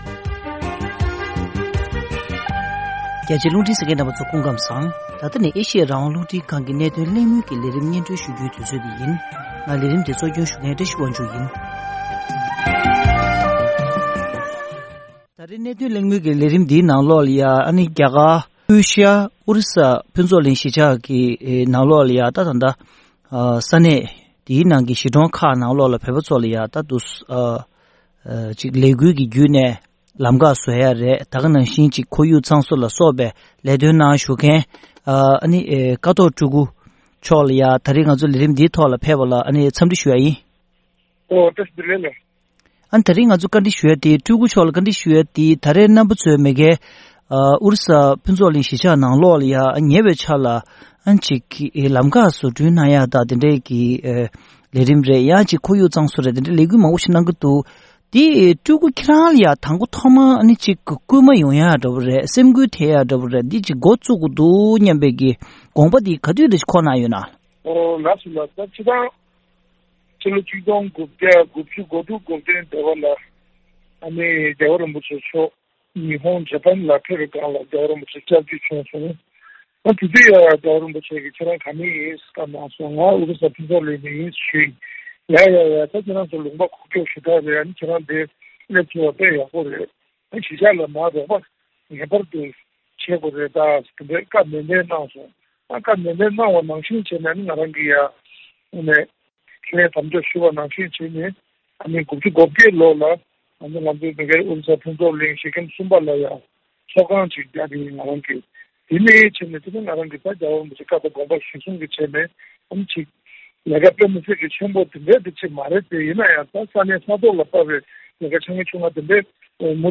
རྒྱ་གར་ཨོ་རི་ས་དང་ཁེ་ན་ཌའི་ནང་ལས་འགུལ་གནང་མཁན་བོད་མི་གཉིས་དང་གླེང་མོལ།